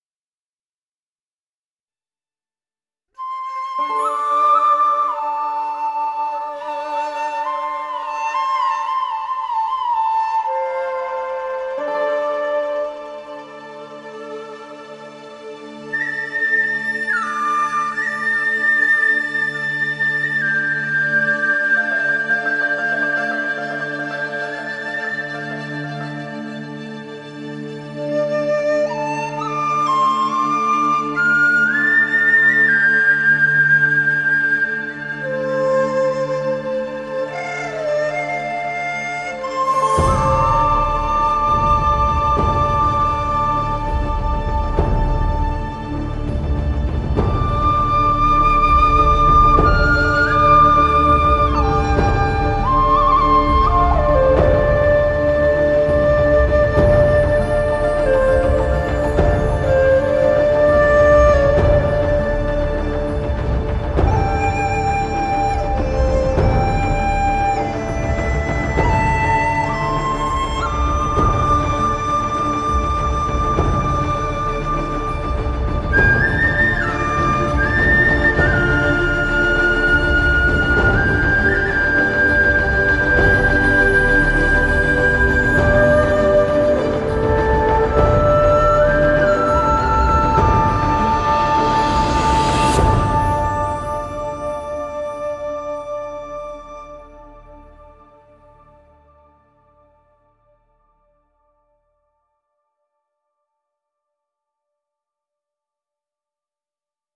这些风笛有着令人难以置信的情感特征，可以为你的音乐增添魔力和灵魂。
这款虚拟乐器包含了 900 多个现场录制的笛子短语，分为 17 个不同的主题。